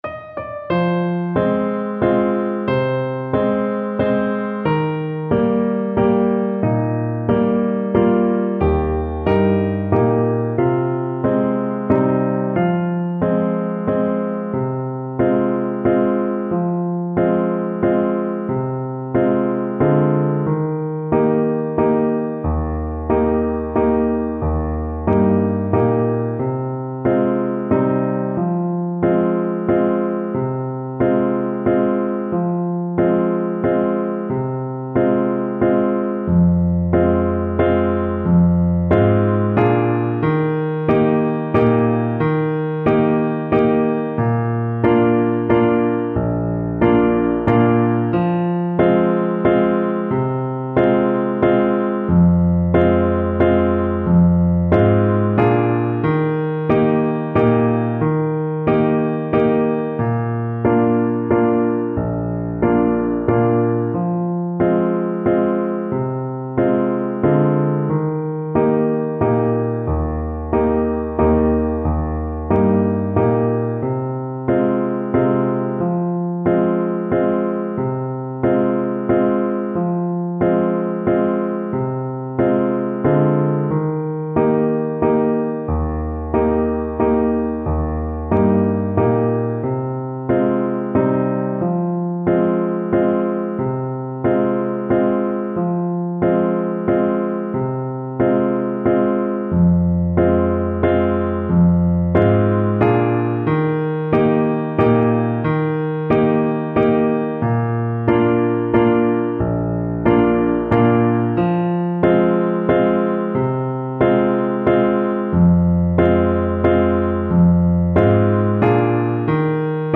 Play (or use space bar on your keyboard) Pause Music Playalong - Piano Accompaniment Playalong Band Accompaniment not yet available transpose reset tempo print settings full screen
Clarinet
Eb major (Sounding Pitch) F major (Clarinet in Bb) (View more Eb major Music for Clarinet )
Slow one in a bar .=c.44
3/4 (View more 3/4 Music)
Traditional (View more Traditional Clarinet Music)